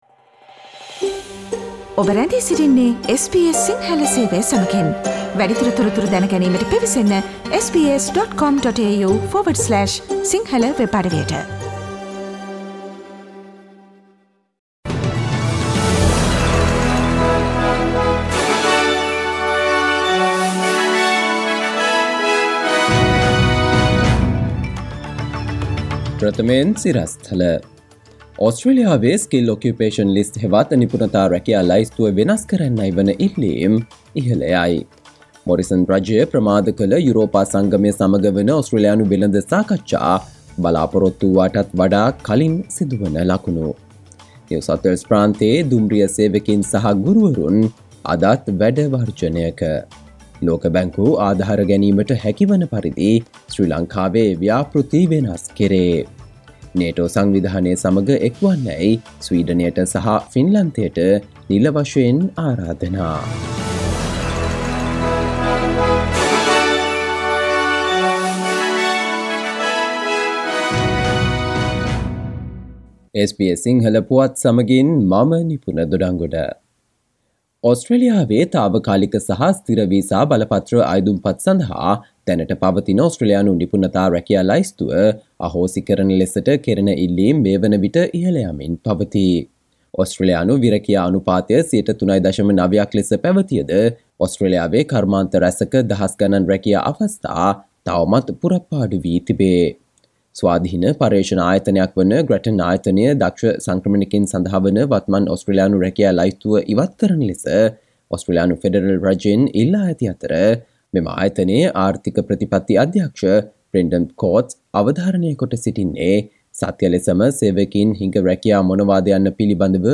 සවන්දෙන්න 2022 ජූනි 30 වන බ්‍රහස්පතින්දා SBS සිංහල ගුවන්විදුලියේ ප්‍රවෘත්ති ප්‍රකාශයට...